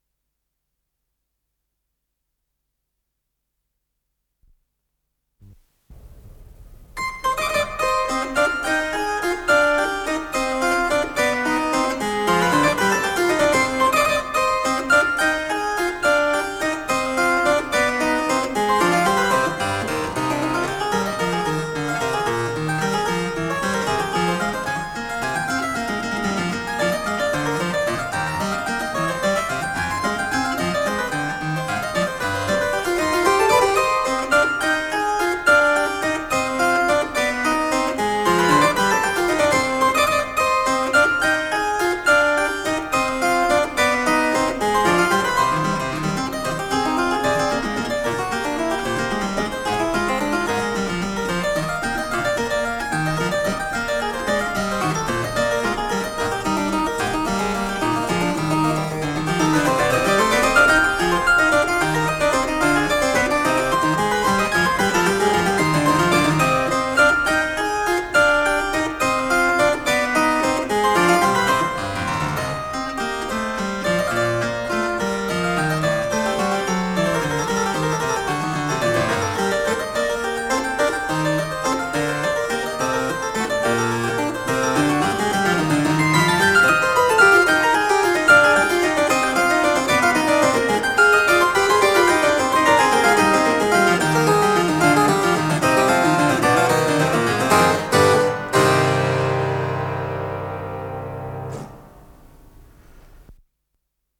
Рондо
ИсполнителиРальф Киркпатрик - клавесин